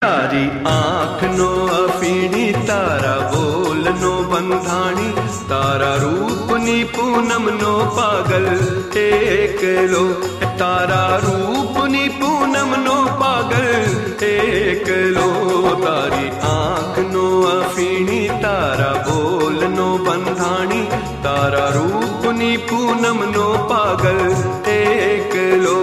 BGM Ringtones